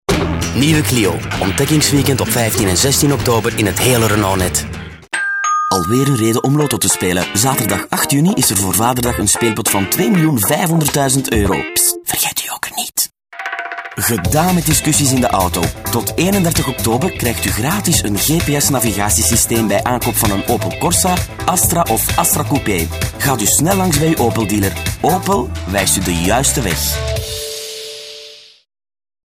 Kraakheldere stem Man , Vlaams